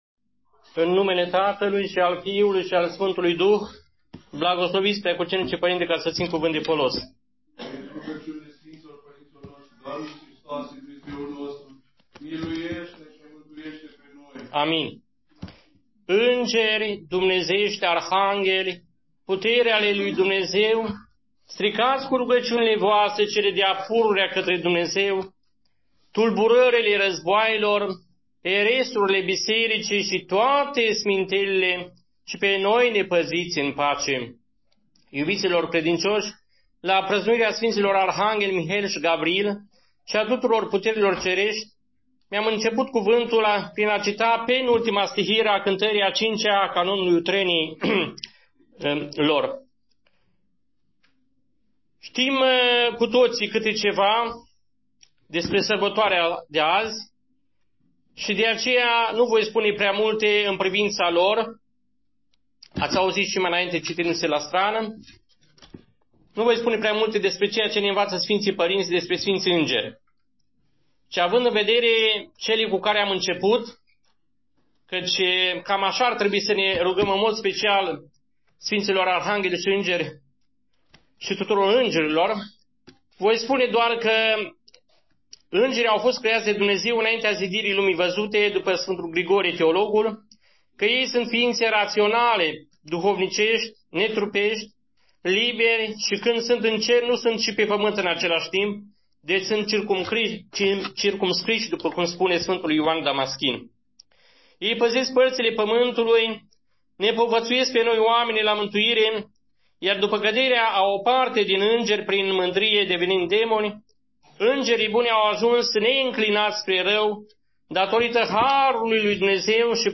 la praznicul Sfinților Arhangheli Mihail și Gavriil